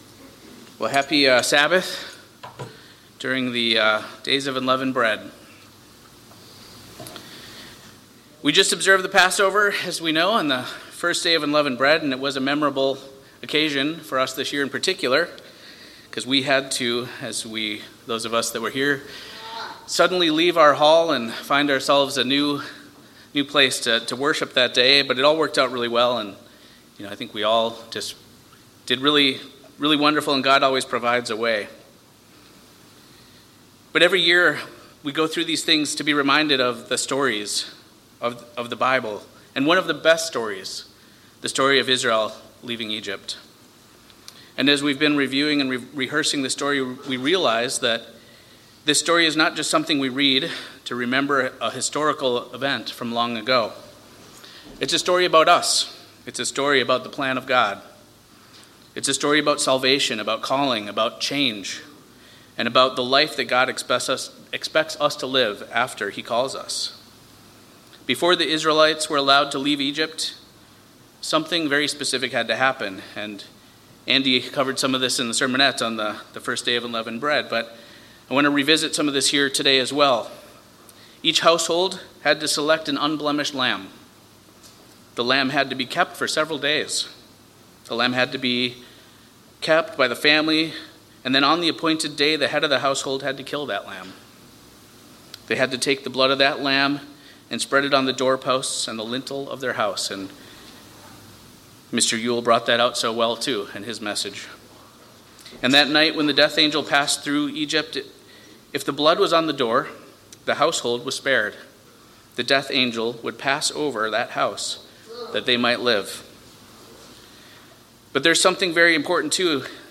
This message explains that Passover and the Days of Unleavened Bread picture the Christian journey: Christ, the perfect Passover Lamb, died so we could be forgiven, and afterward we are called to leave sin behind and live transformed lives.